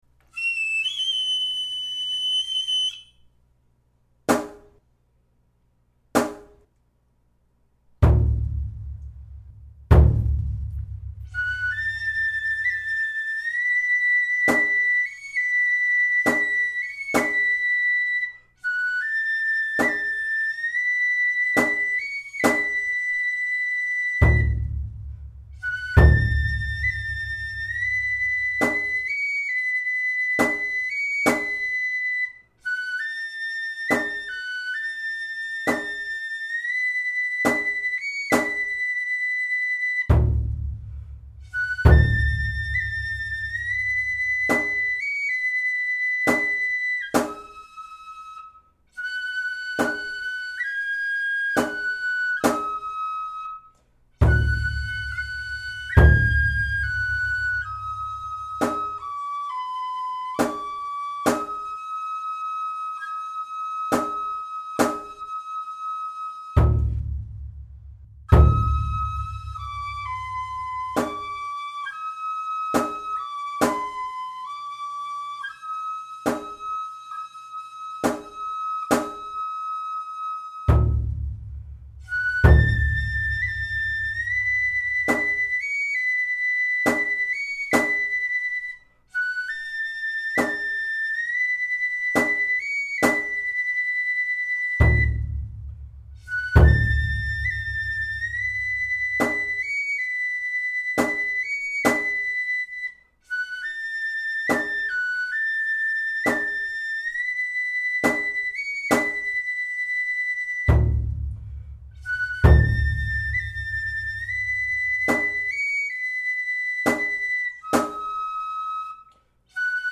このページの音源は、あくまで練習用に録音したもので、装飾音符をすべて省いて、きわめて簡略化された形で吹いています。
太鼓は、コンピューターで作成したもので、リズムは完璧ですが、やや無味乾燥です。
吹き出しの高音や太鼓の入りは、知立でよく使われているパターンでやっています。
神納めだけは６８です。
笛・太鼓